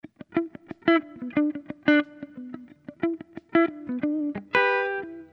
Loops guitares rythmique- 100bpm 3
Guitare rythmique 60